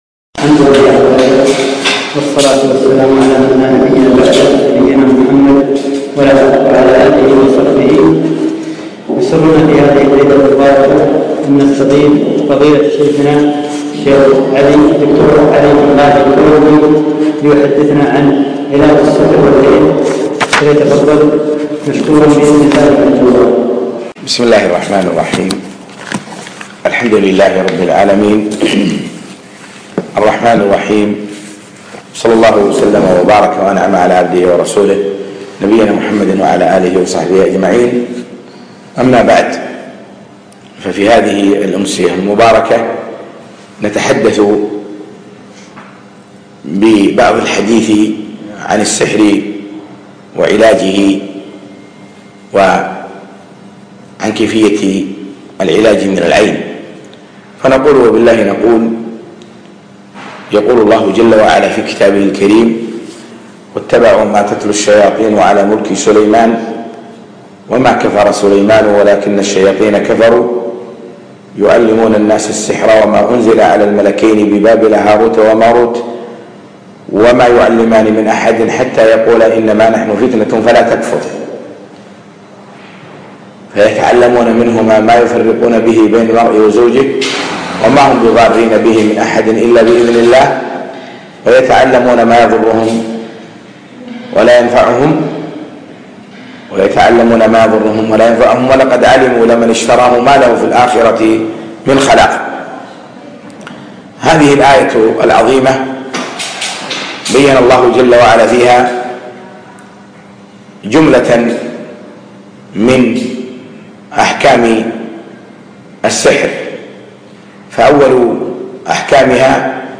علاج السحر والعين - محاضرة